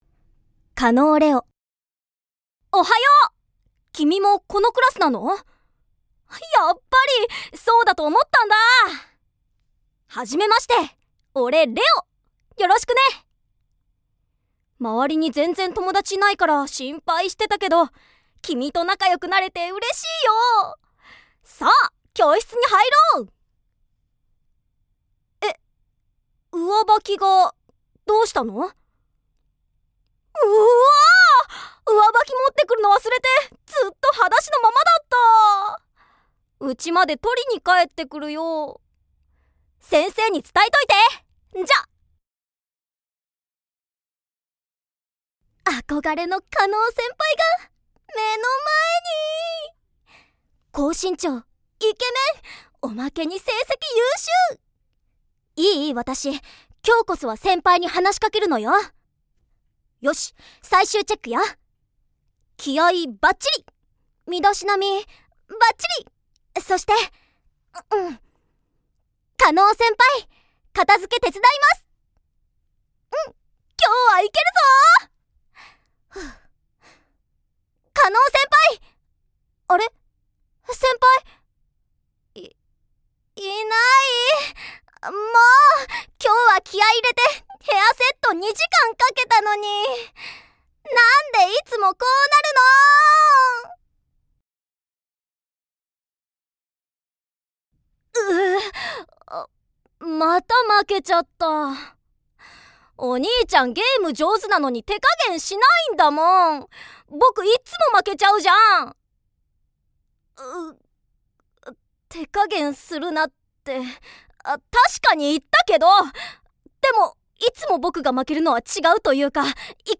方言　 　： 関西弁
◆台詞